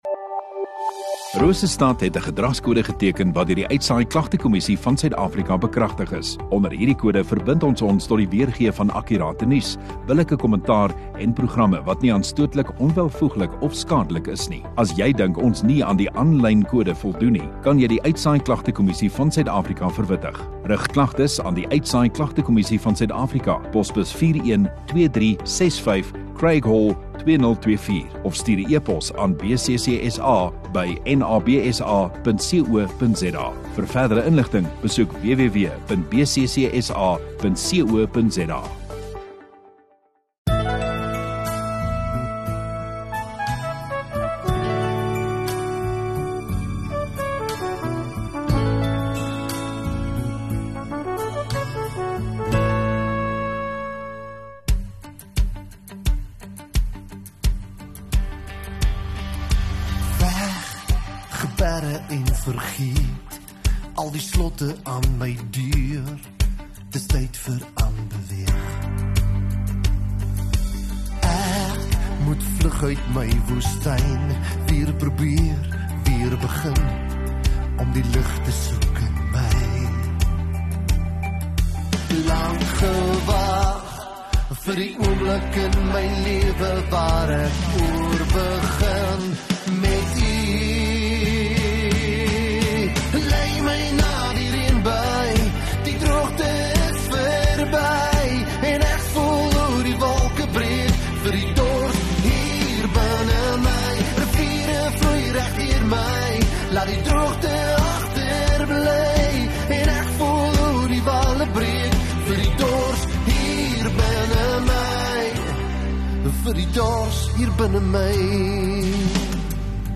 17 Aug Sondagoggend Erediens